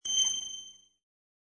new_mail_sound.wav